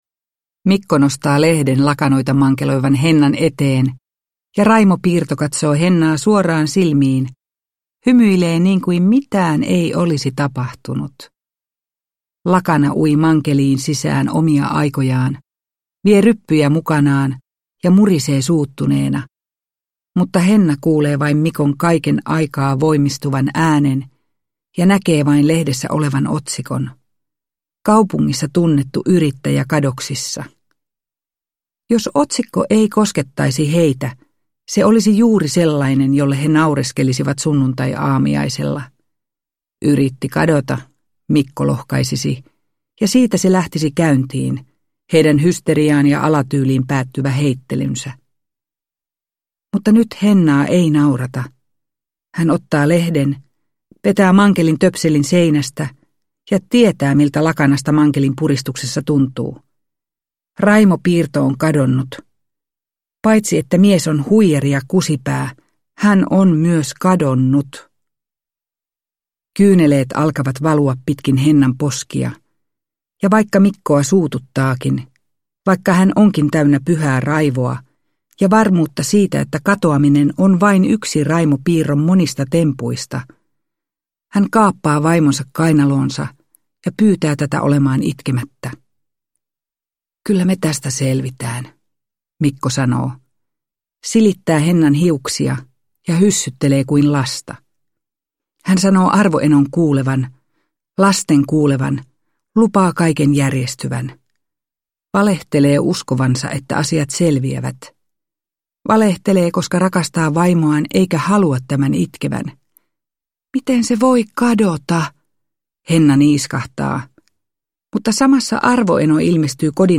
Loppu – Ljudbok – Laddas ner
Uppläsare: Eppu Nuotio